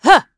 Isolet-Vox_Attack5.wav